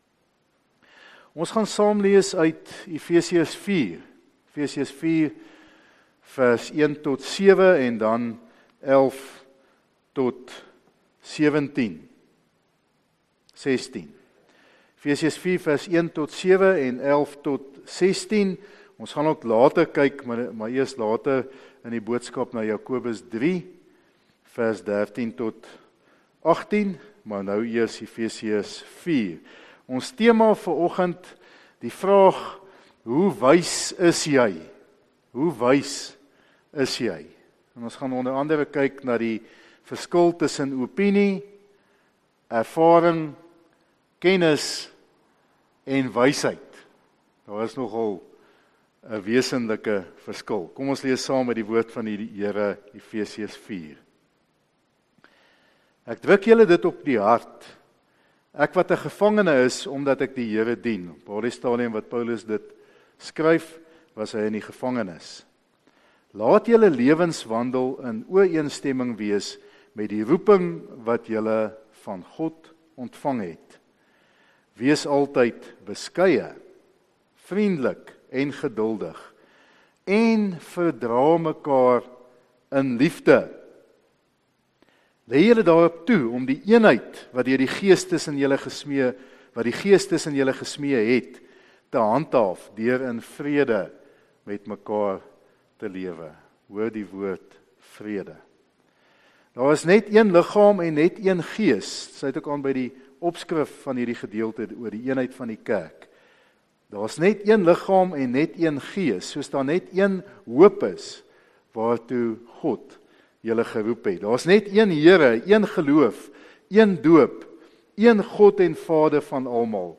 Erediens